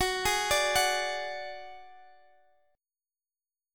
Listen to F#sus2#5 strummed